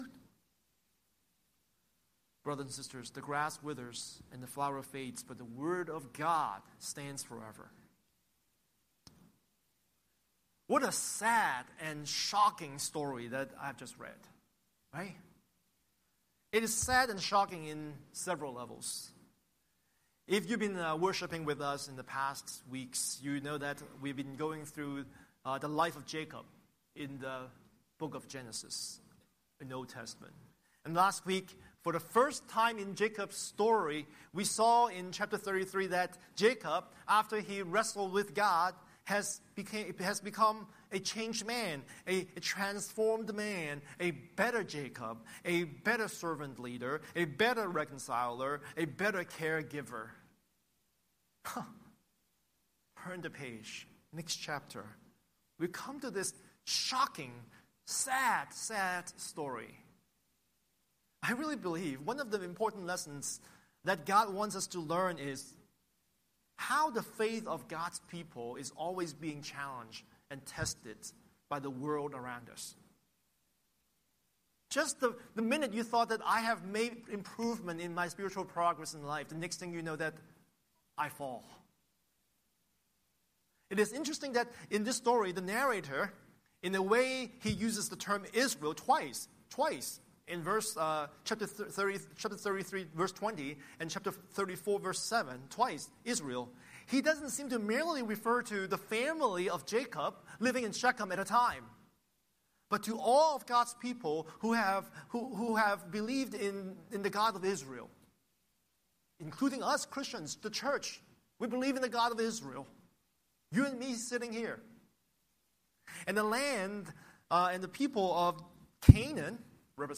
Scripture: Genesis 33:19–34:31 Series: Sunday Sermon